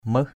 /møh/